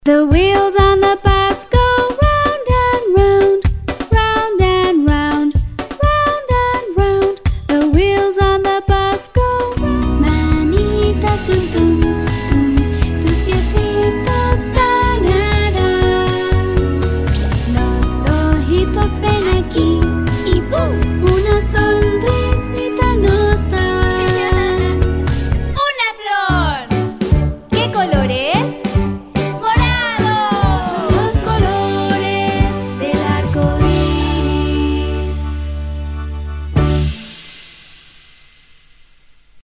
女声
童谣儿童歌曲 积极向上|时尚活力|亲切甜美